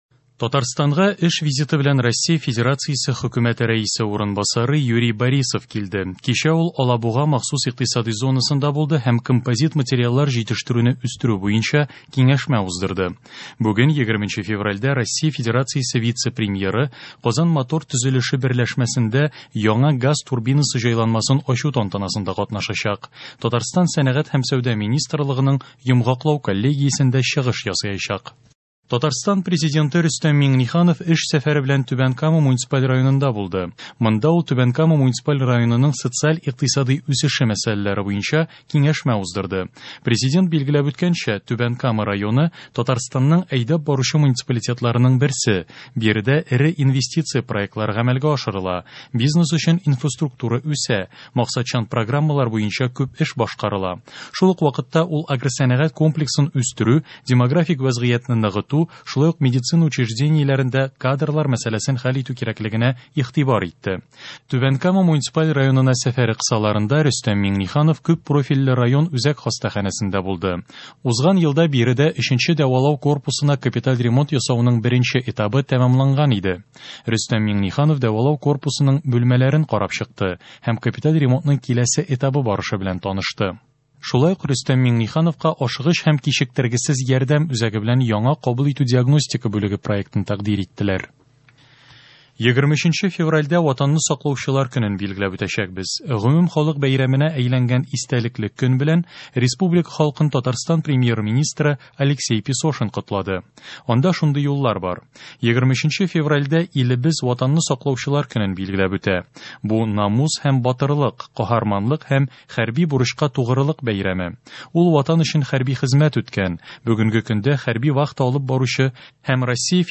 Яңалыклар (20.02.21)